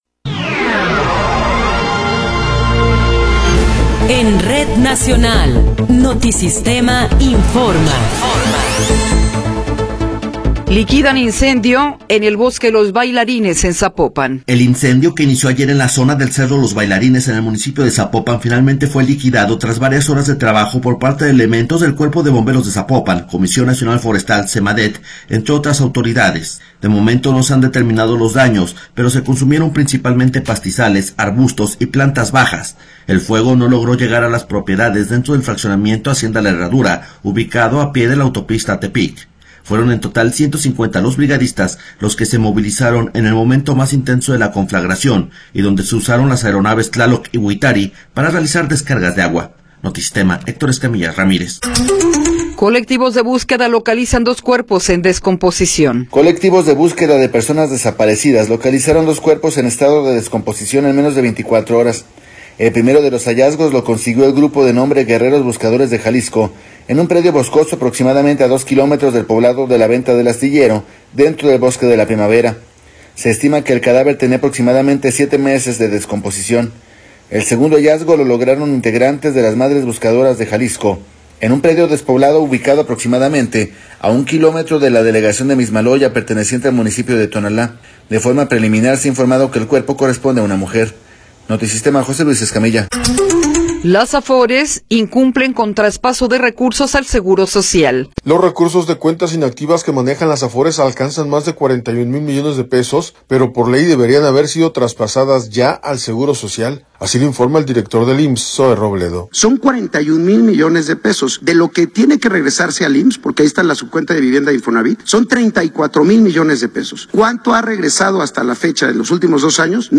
Noticiero 10 hrs. – 19 de Abril de 2024